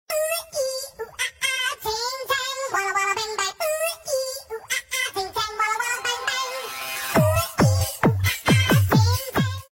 versi anak bebek lucu menggemaskan